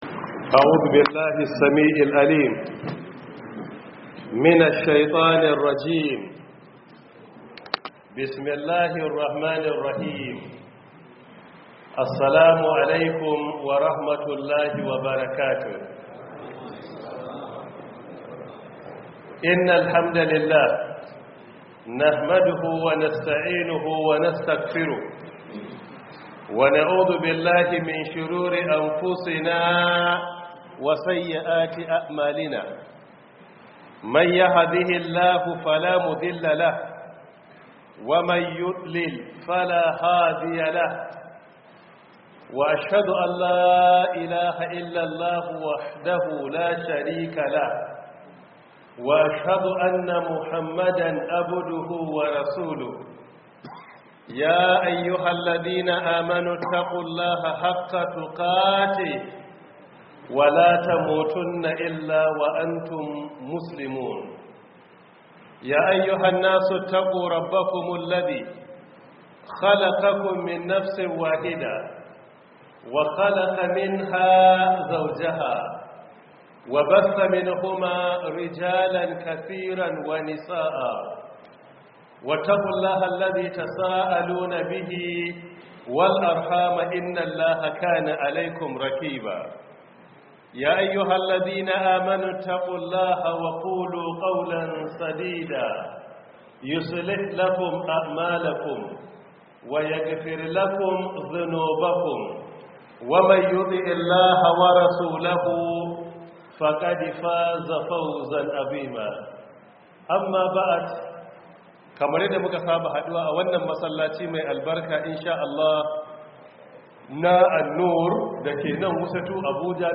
Download Audio From Book: 1447/2026 Ramadan Tafsir